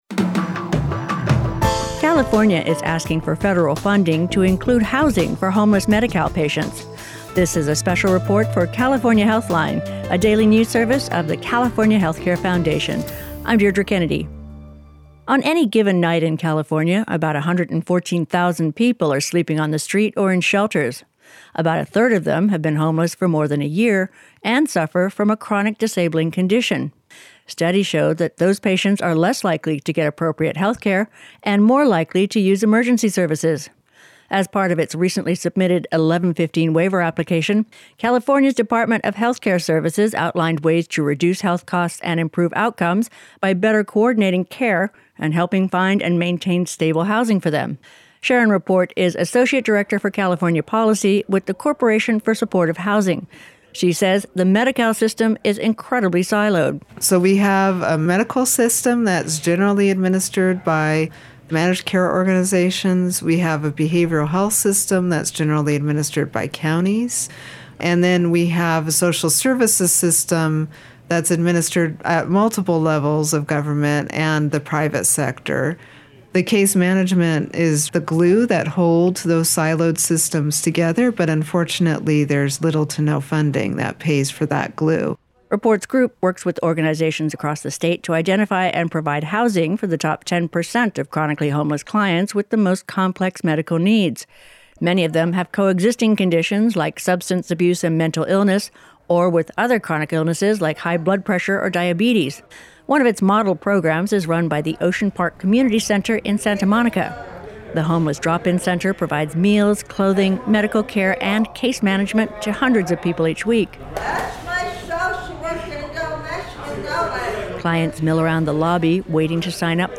The report includes comments from:
Audio Report Insight Multimedia